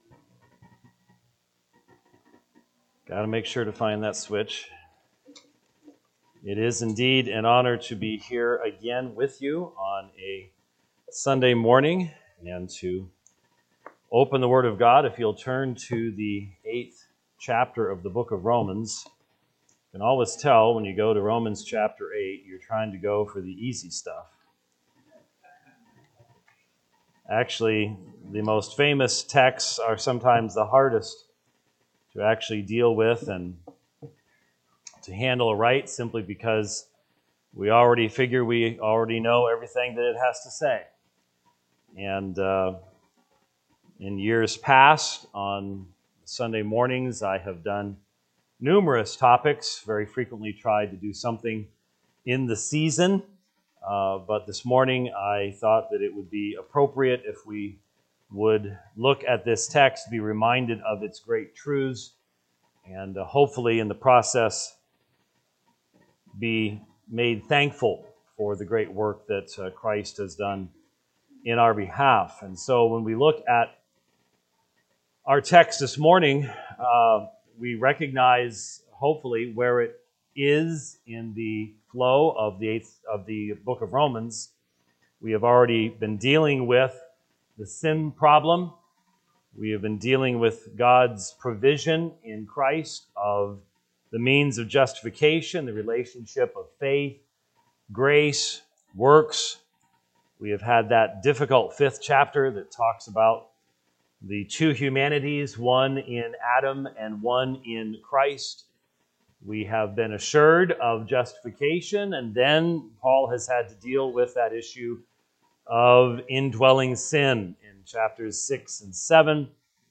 Sunday-Sermon.mp3